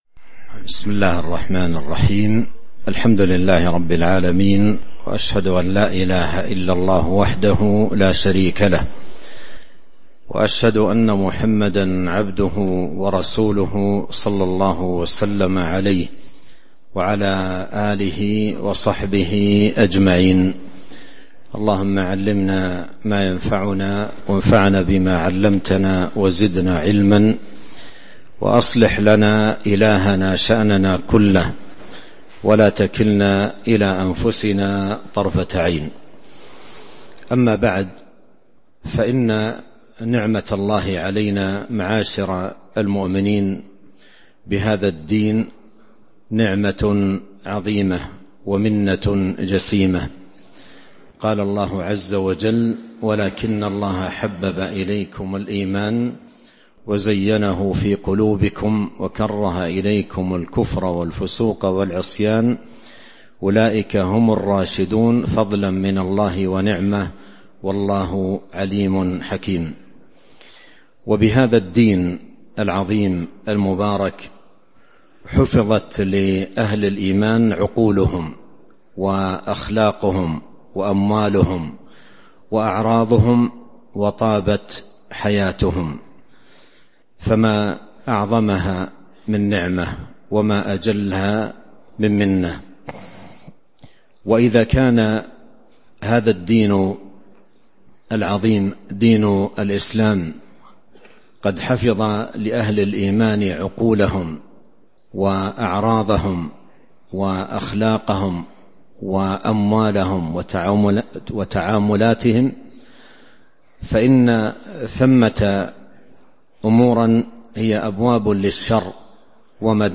تاريخ النشر ١٧ شوال ١٤٤٤ هـ المكان: المسجد النبوي الشيخ